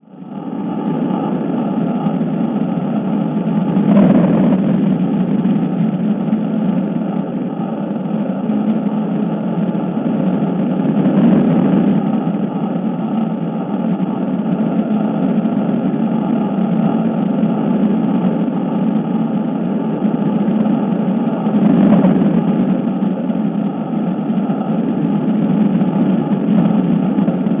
sound produced at the vent.
volcano.wav